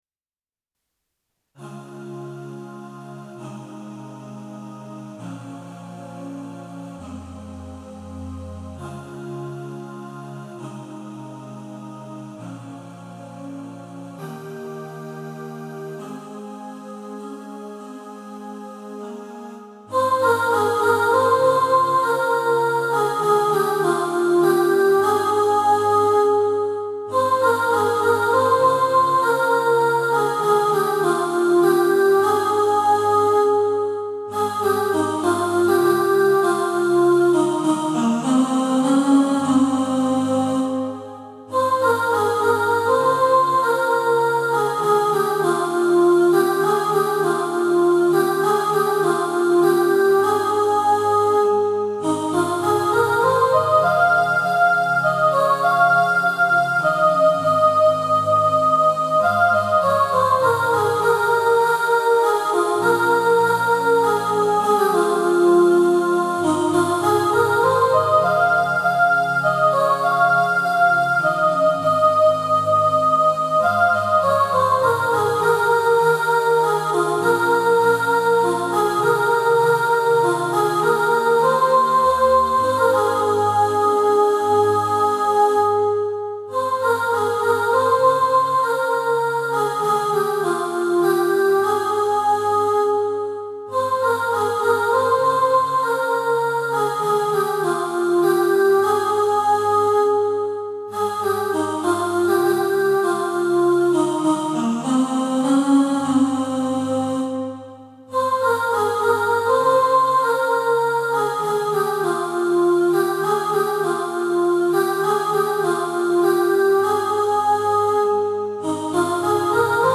Move-On-Soprano.mp3